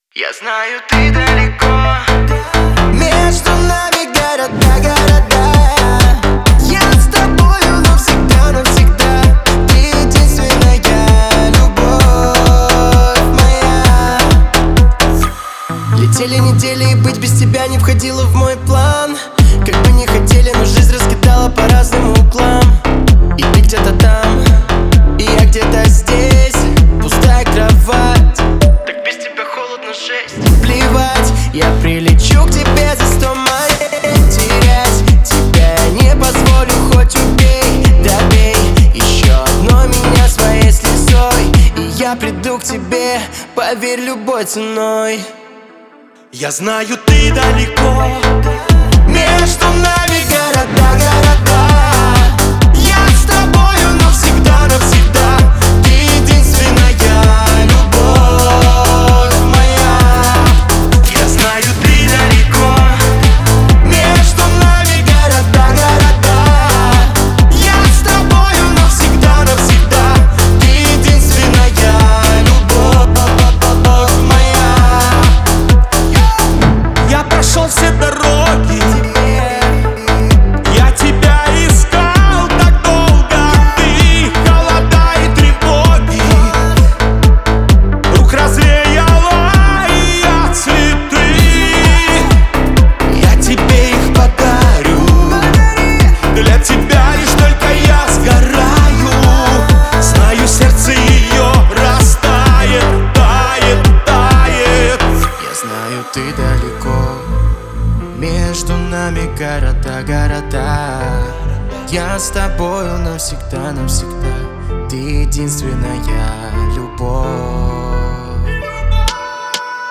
Танцевальная музыка
музыка для дискотеки , танцевальные песни